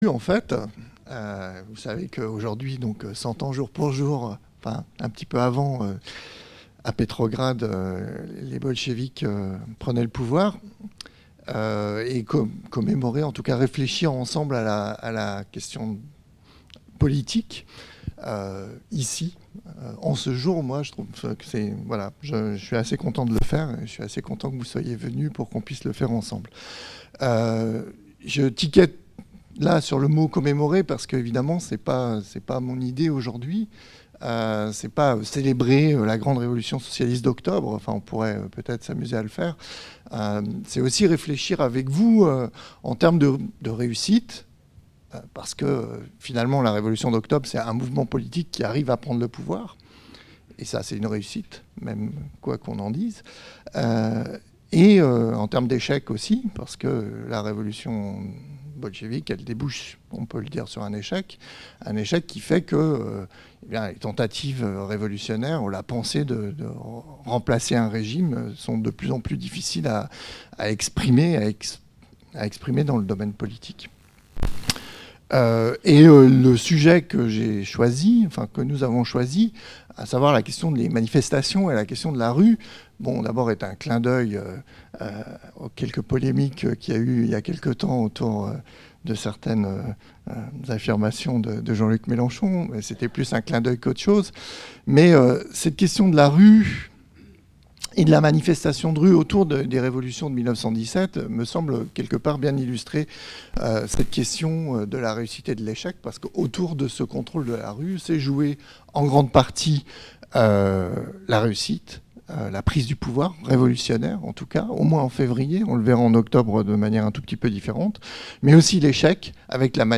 Vous pouvez réécouter la première soirée intitulée Le pouvoir est-il dans la rue ?